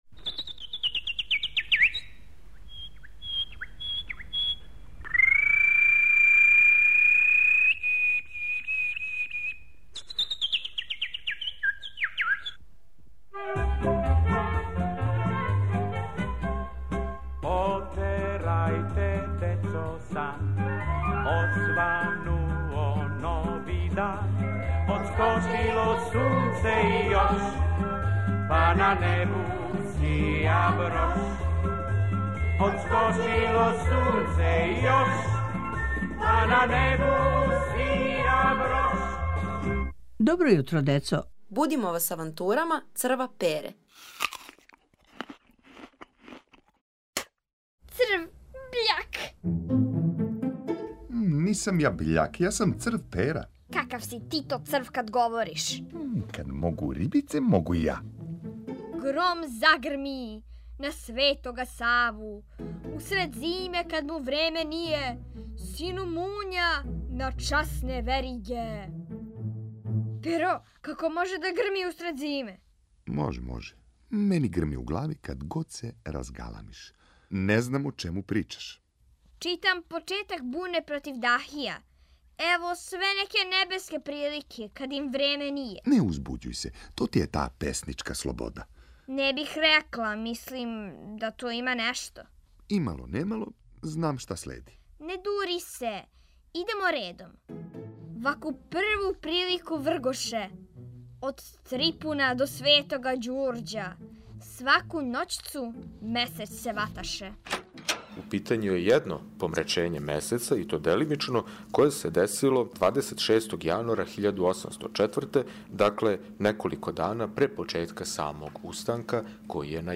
У овој авантури, радознало дете ће рецитовати народну поезију а намћорасти црв ће се нервирати... зашто и којим поводом?